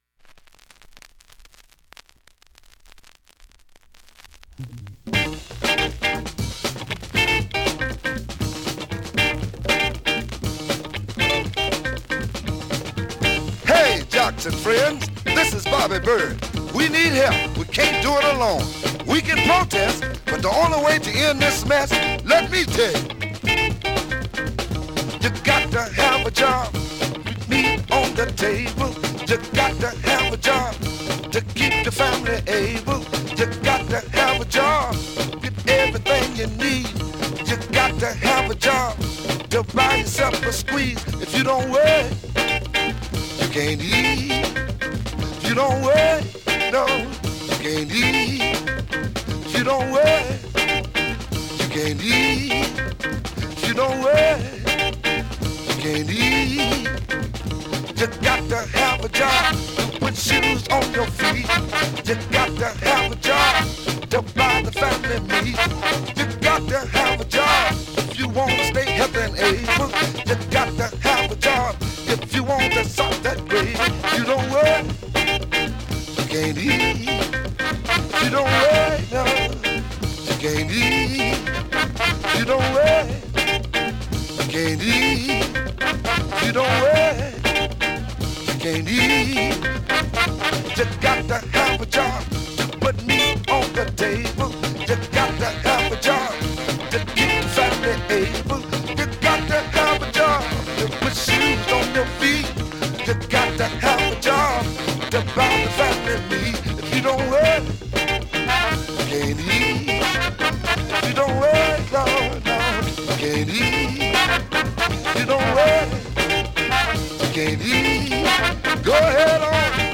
現物の試聴（両面すべて録音時間６分２２秒）できます。